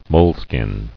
[mole·skin]